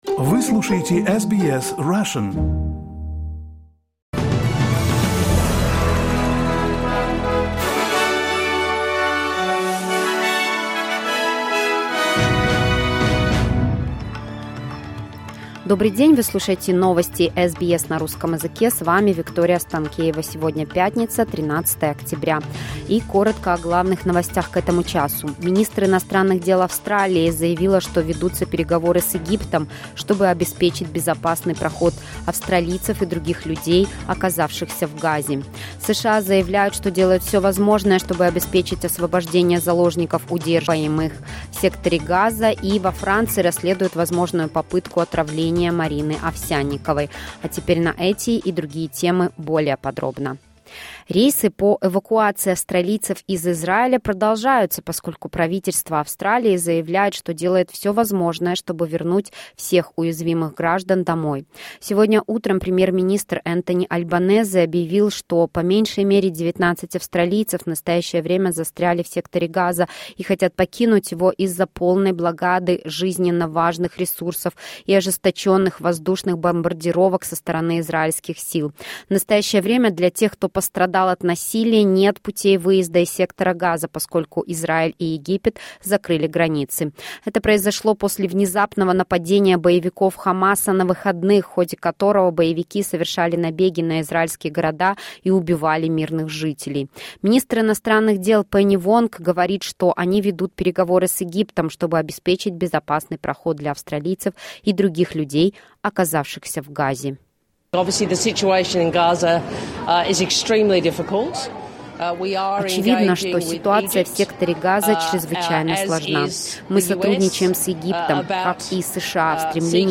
SBS news in Russian — 13.10.2023